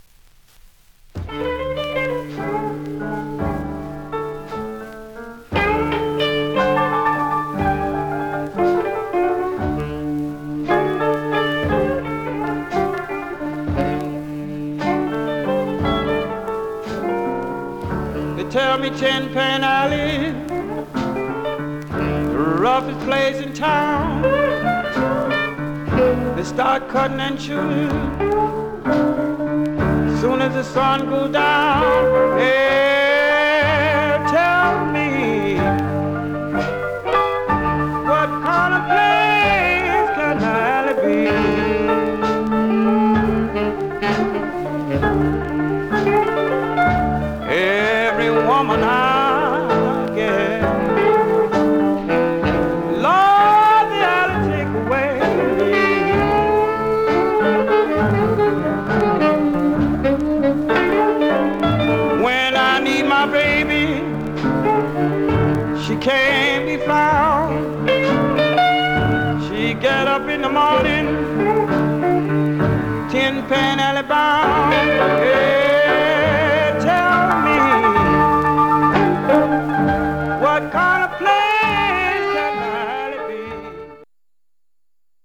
Mono
Rythm and Blues